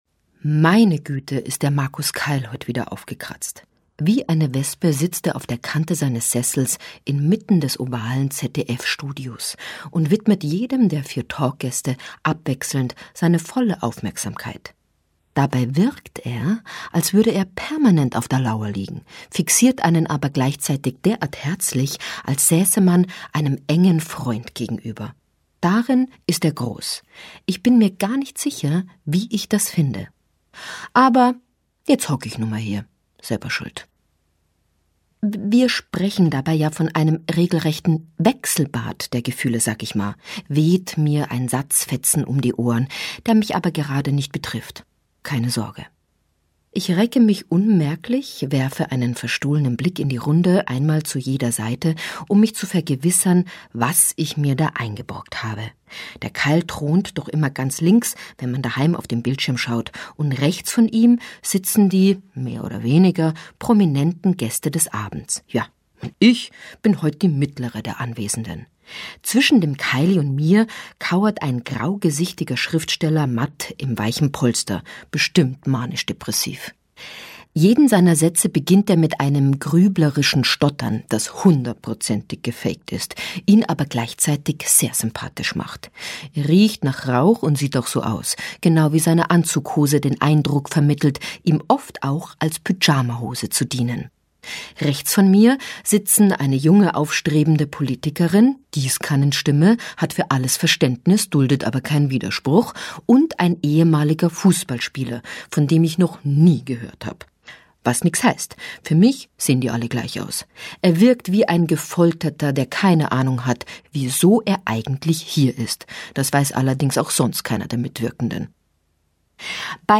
Lesung mit Brigitte Hobmeier (1 mp3-CD)
Brigitte Hobmeier (Sprecher)